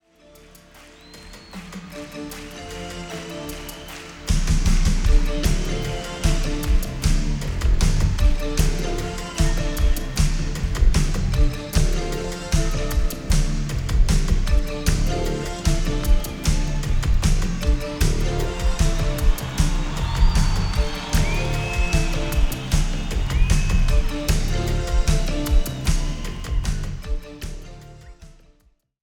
Compare Sample from original CD to newly Digital Refresh.